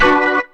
B3 BMIN 2.wav